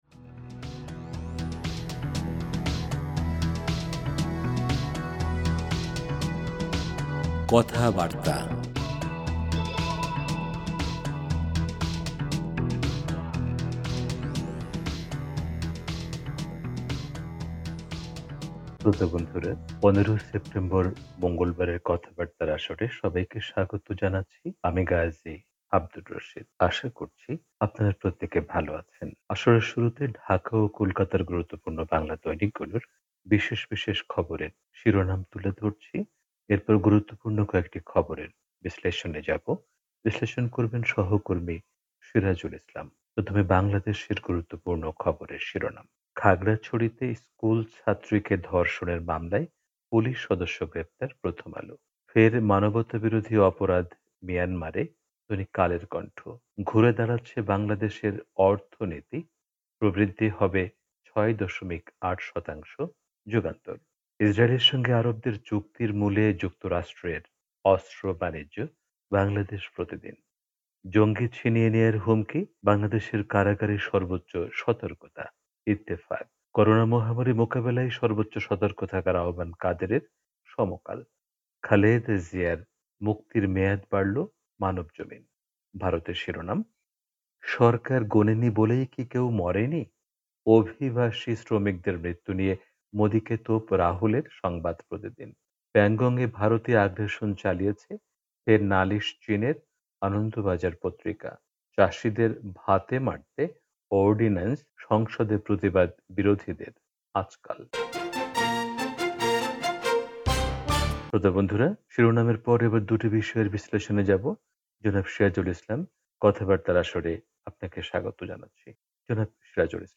রেডিও